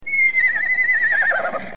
Die Farmerama Tierstimmen
Pferd.wav.mp3